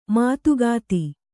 ♪ mātugāti